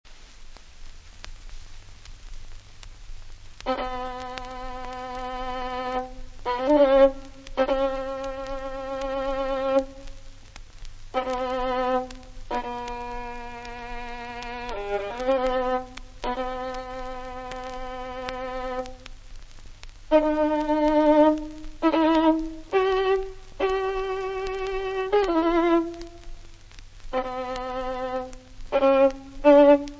• rapsodie
• Rhapsody